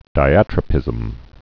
(dī-ătrə-pĭzəm)